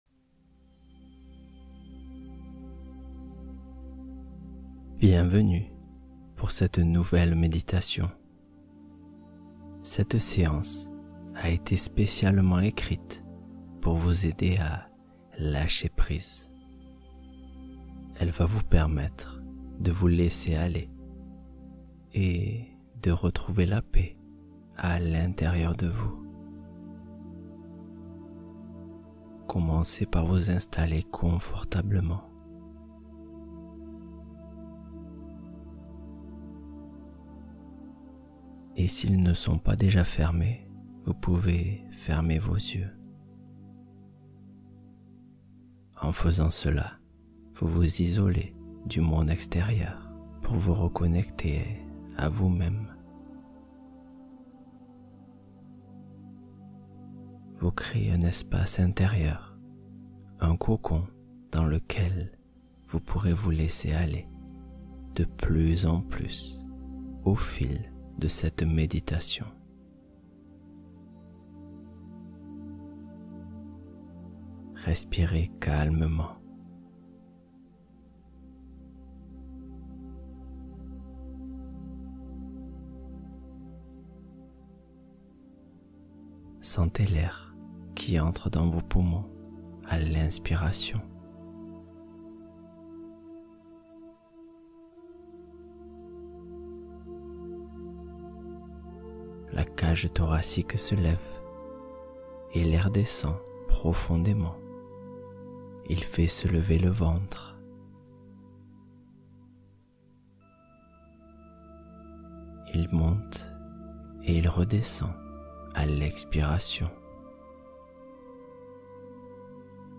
Affirmations apaisantes — Relâchement profond du corps et de l’esprit